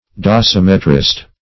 -- Do`si*met"ric , a. -- Do*sim"e*trist , n. [Webster 1913 Suppl.]